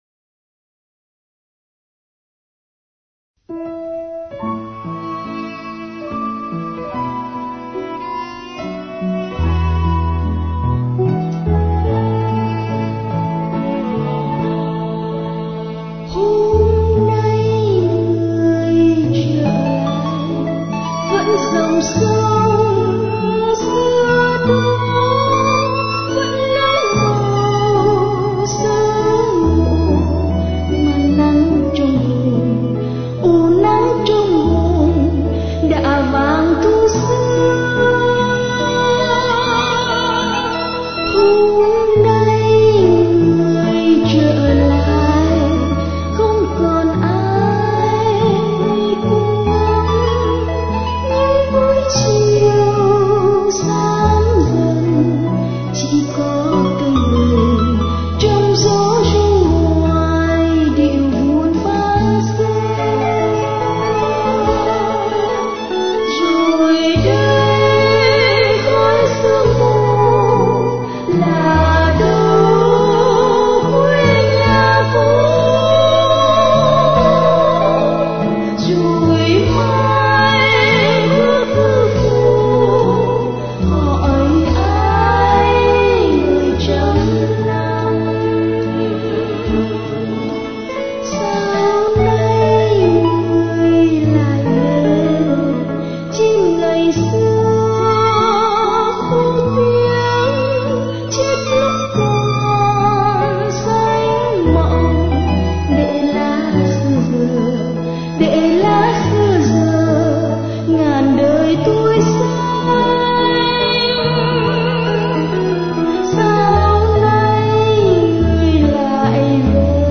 piano
violon